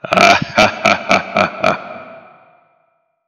haha.wav